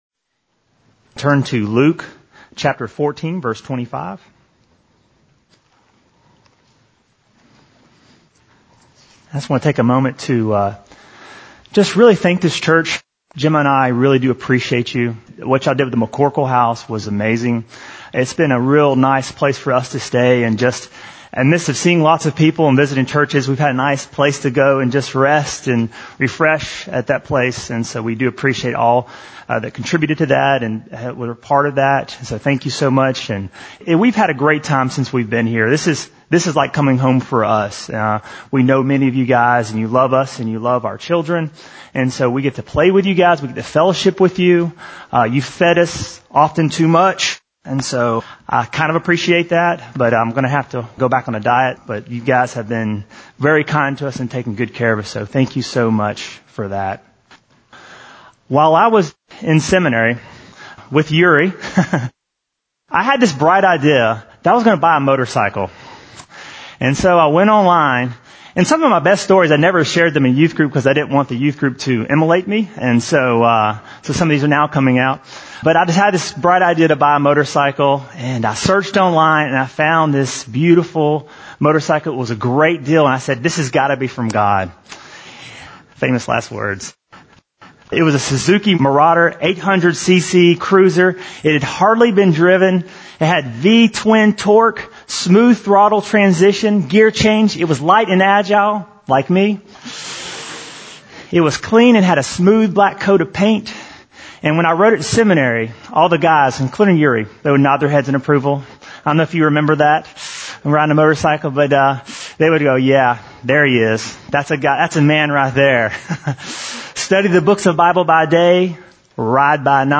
Bible Text: Luke 14:25-33 | Preacher: Visiting Pastor